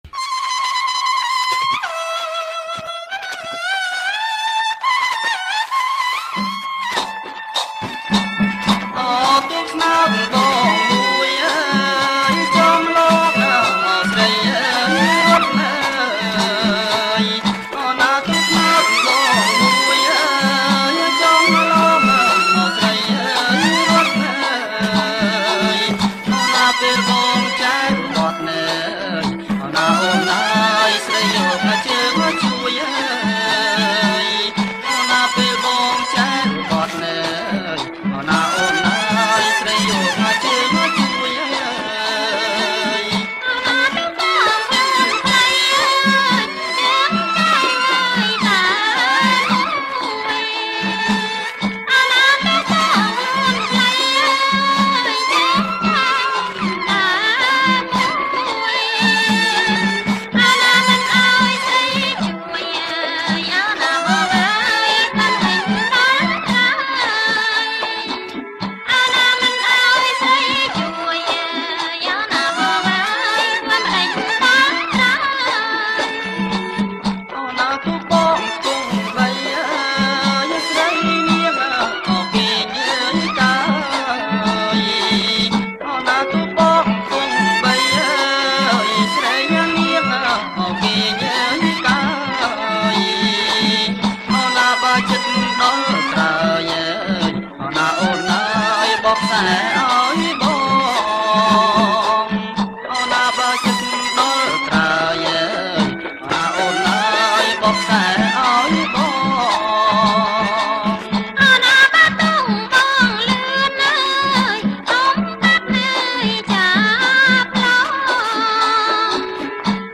ប្រគំជាចង្វាក់ សារ៉ាវ៉ាន់